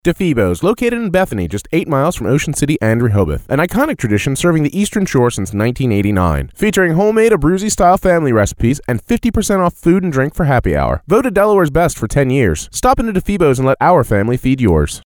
MXL 770 Condenser Microphone, Focusrite Scarlett 2i4 Audio Interface, DBX 286S Preamp/Processor, Adobe Audition Creative Cloud.
Dry Vocal Tracks
difebos_2017_dry.mp3